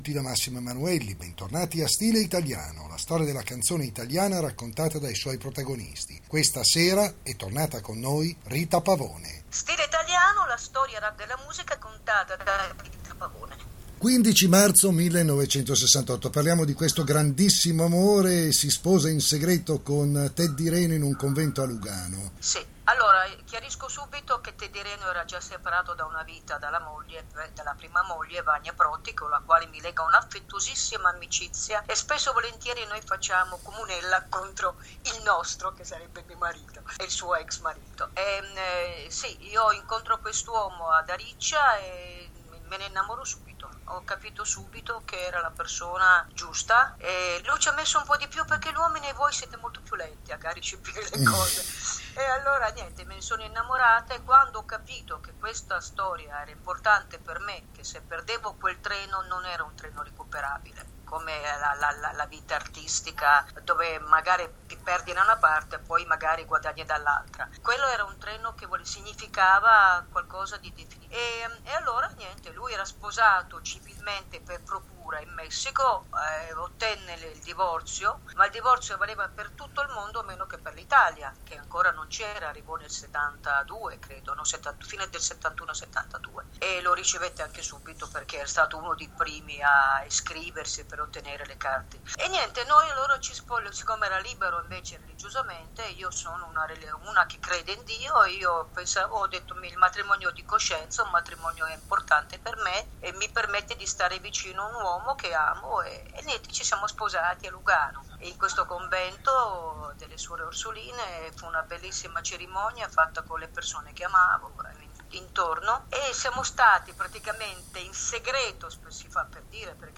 rita-pavone-intervista-ii-parte.mp3